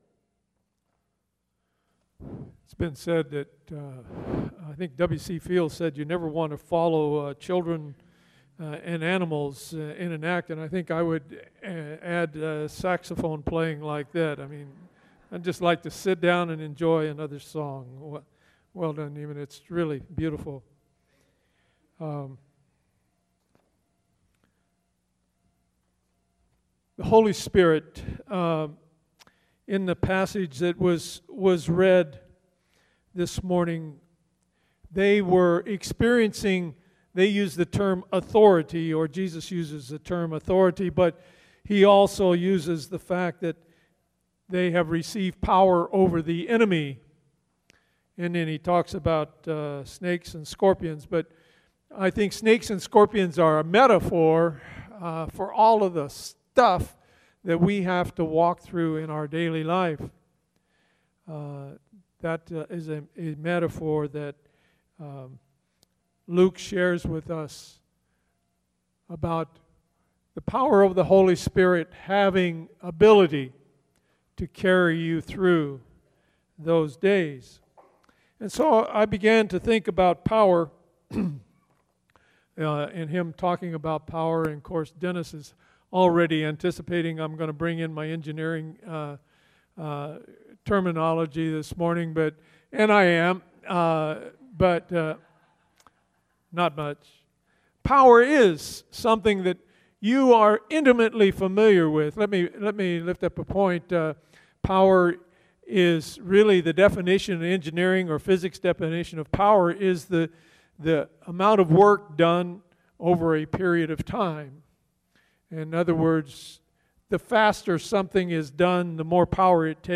Pentecost Sunday.